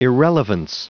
Prononciation du mot irrelevance en anglais (fichier audio)
irrelevance.wav